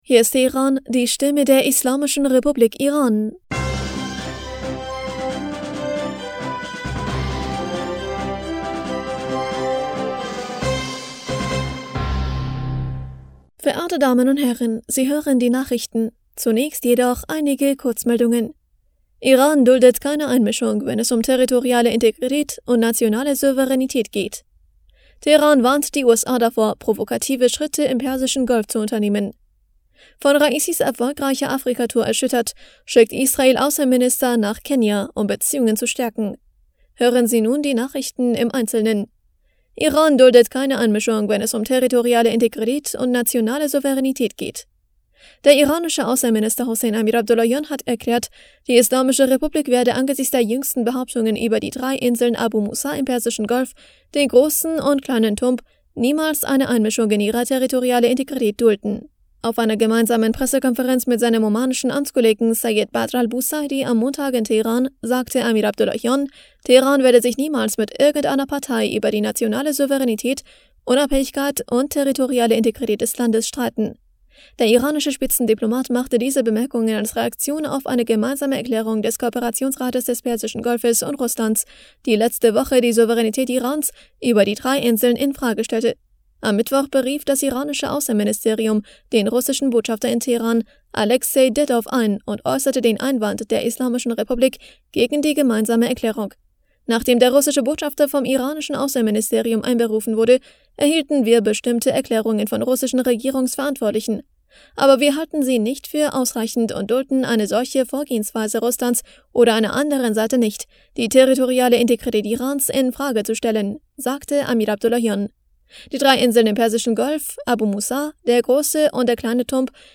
Nachrichten vom 18. Juli 2023
Die Nachrichten von Dienstag, dem 18. Juli 2023